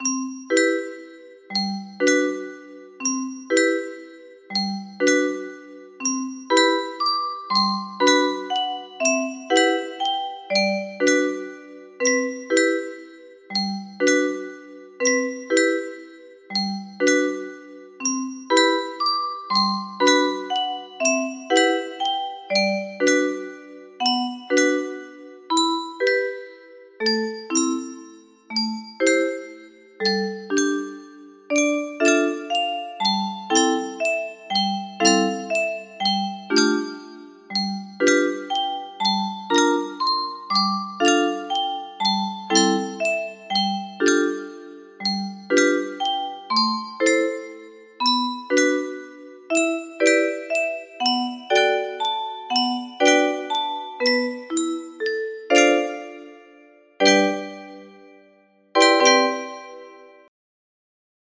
Erik Satie-Gymnopedie No.1 music box melody